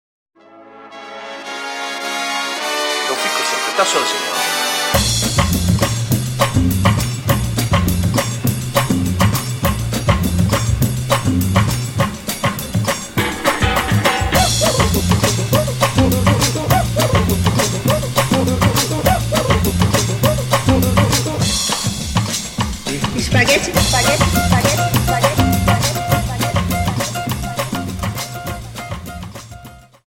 Dance: Samba Song